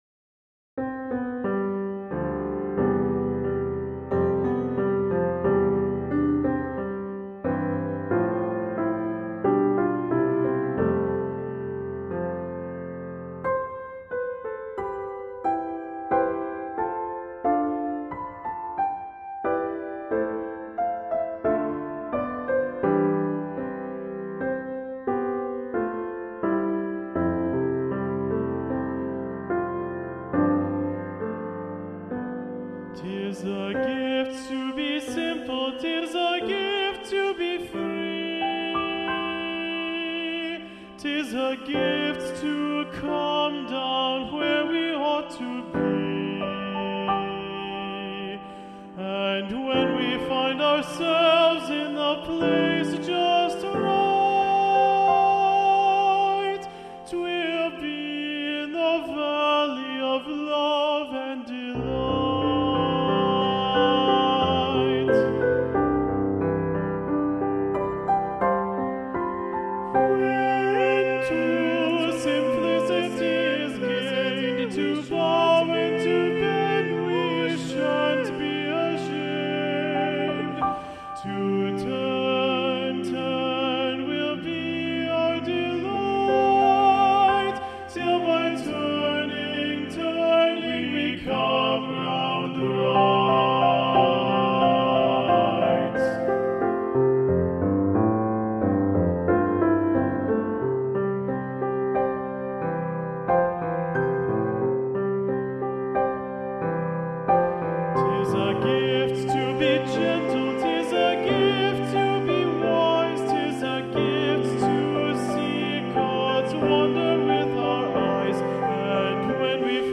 Choral Music
GENERAL MUSIC — WITH PIANO